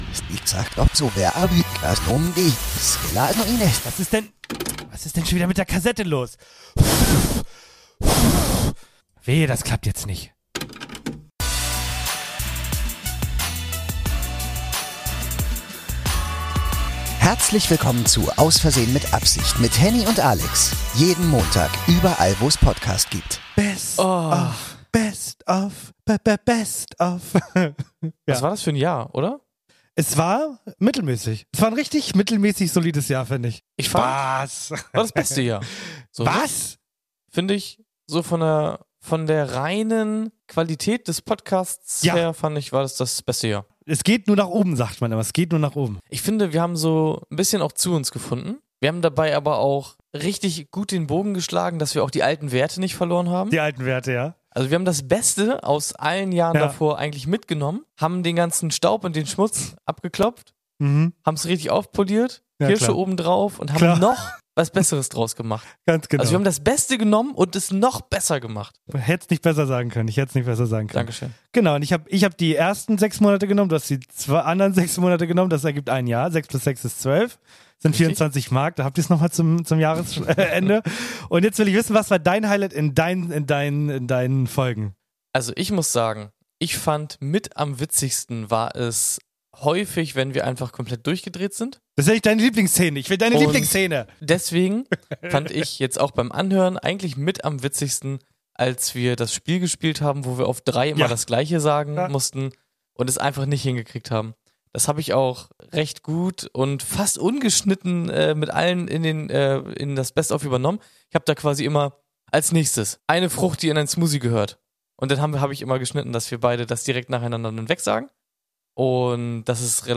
Dann go, hier kommt das Beste von uns aus dem Jahr 2024. Es gibt Habicht Jokes, Räuber die Rhymen, einen Hamster, Gäste, Intros, Songs, grenzwertigen Humor, Ausraster, Haarthur und was nicht noch alles, viel Spaß!